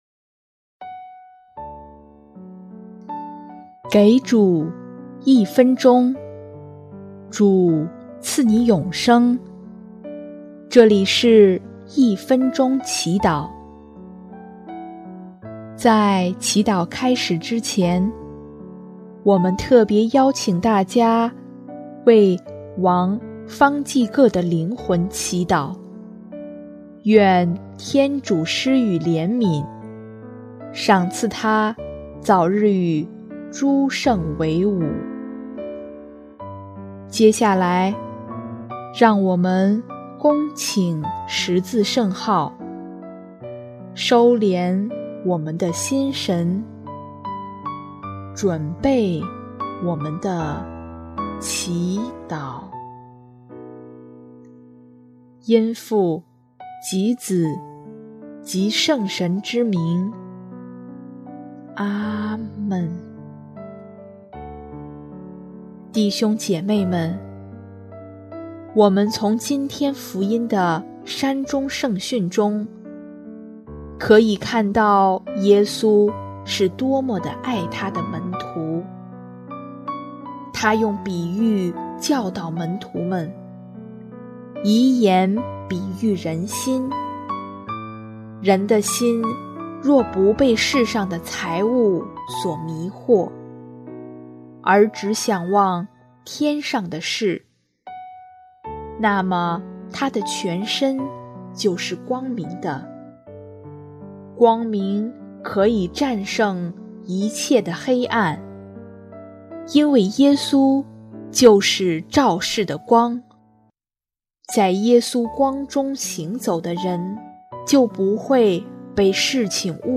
【一分钟祈祷】|6月23日 在光明的身心里，认出祂的面容